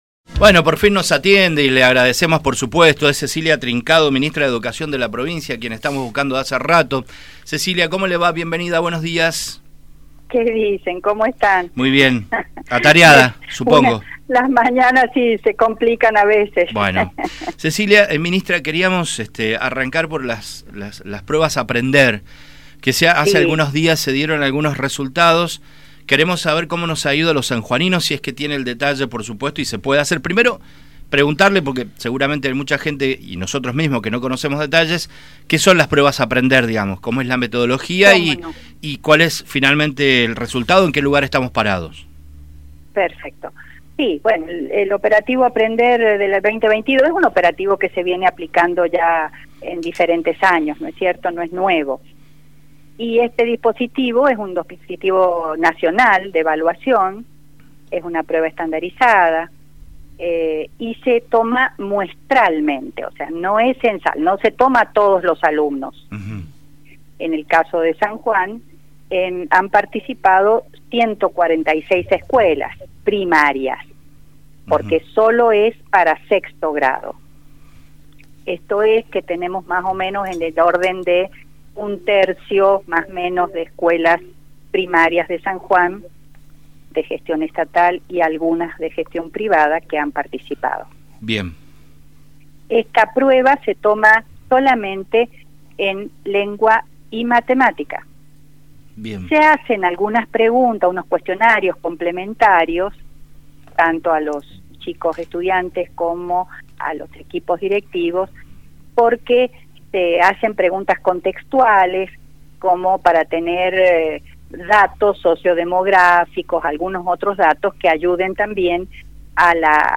En el día de hoy, Cecilia Trincado, ministra de Educación de la provincia, en diálogo con Radio Sarmiento, habló sobre las Pruebas Aprender